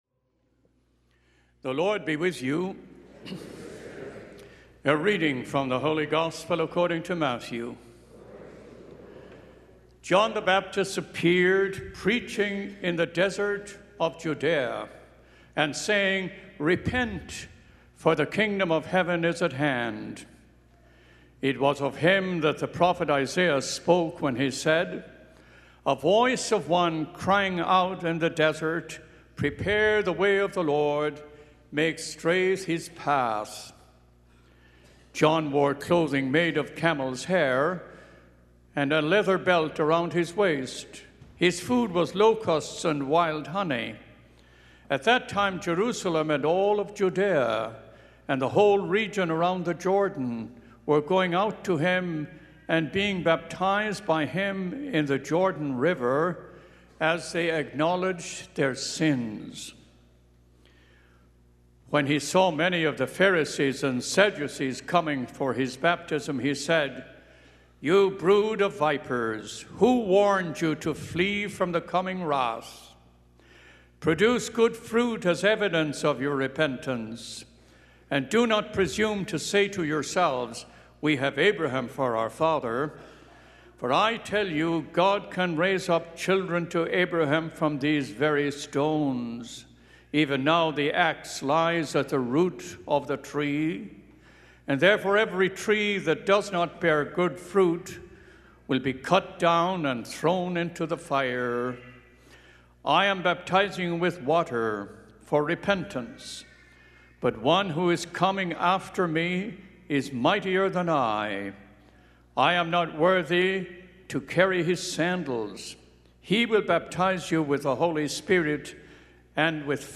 Gospel and Homily Podcasts
Holy Family Church The Solemnity of Our Lord Jesus Christ, King of the Universe, November 26, 2017, 11:15 Mass Play Episode Pause Episode Mute/Unmute Episode Rewind 10 Seconds 1x Fast Forward 10 seconds 00:00 / 14:42 Subscribe Share